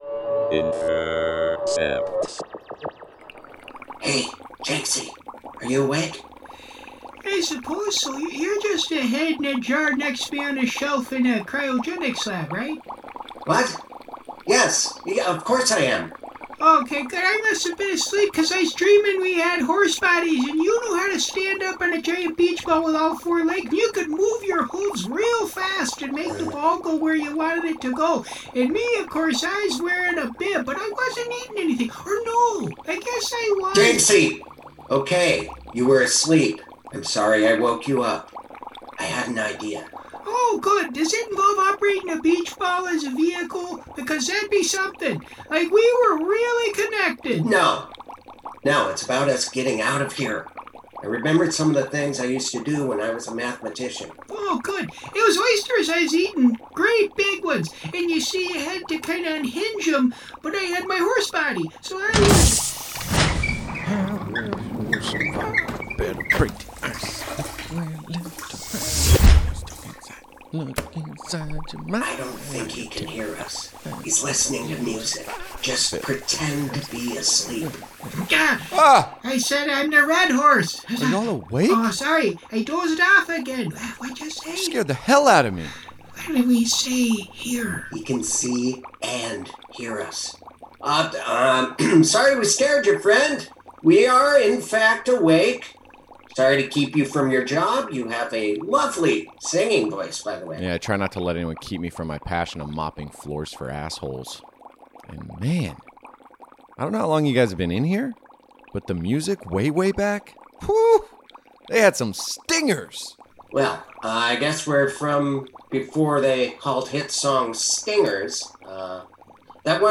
A scripted sketch series